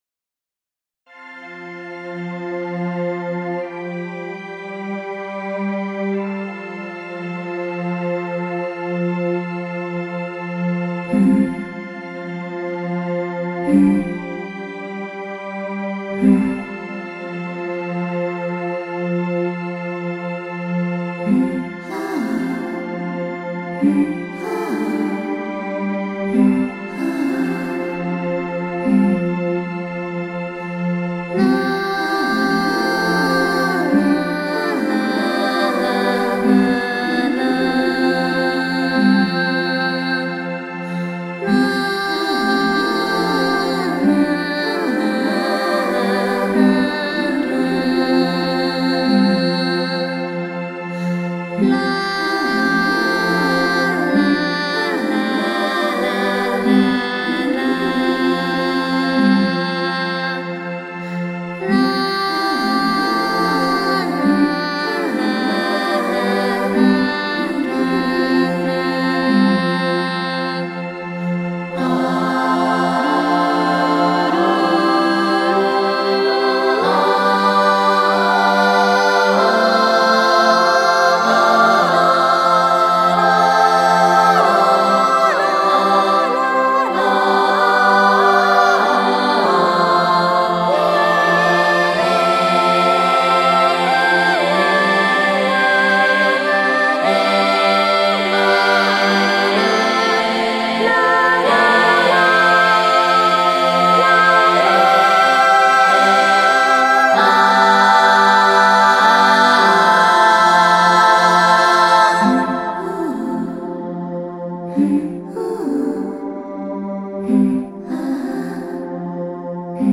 ヴォーカル、というよりは、ハミングヴォーカルorコーラスといった方が正しいんだけど、結構好きです。
下のラインは本当は男性Voが良かったんですが、あいにく男性の歌い手で知り合いがいないもので……少し残念です。